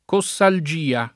cossalgia [ ko SS al J& a ]